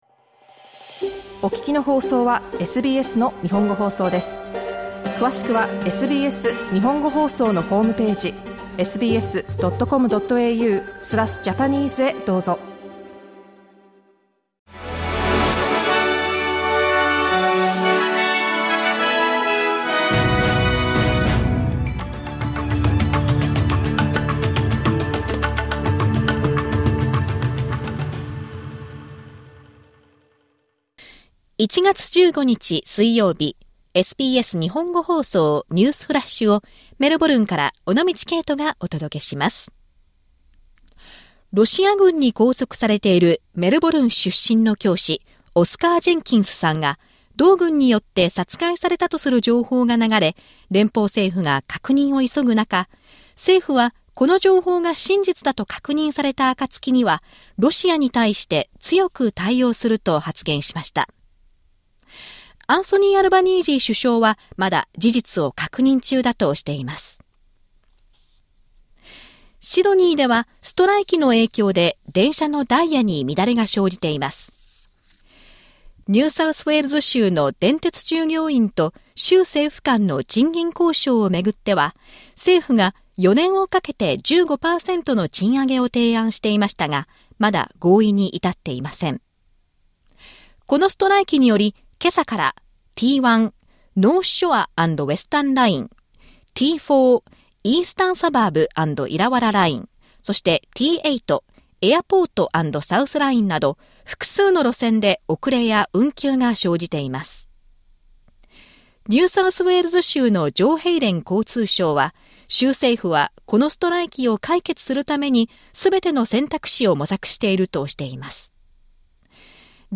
SBS日本語放送ニュースフラッシュ 1月15日 水曜日